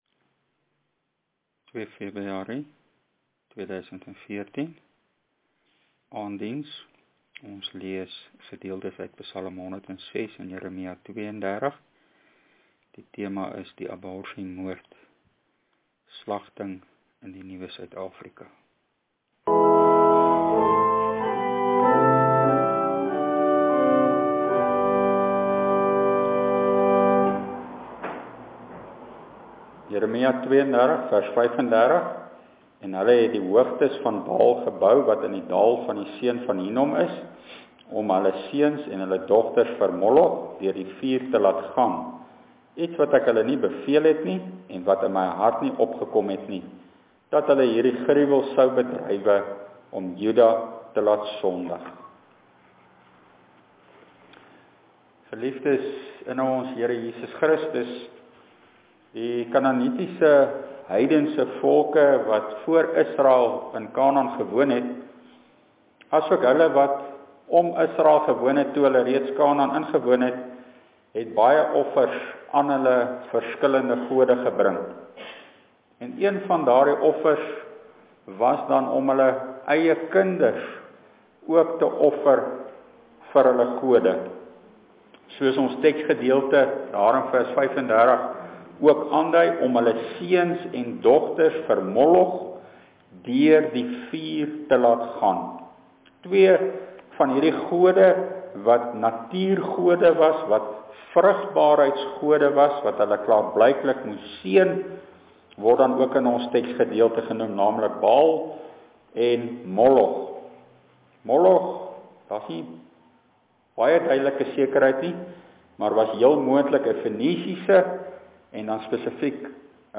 Ek het hierdie preek gepreek om hierdie saak aan te spreek, mag die Here ons genadig wees in sy regverdige oordeel, en ware bekering en reformasie in Christus skenk.